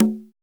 626 CGA LO.wav